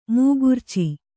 ♪ mūgurci